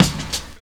43 KICK 2.wav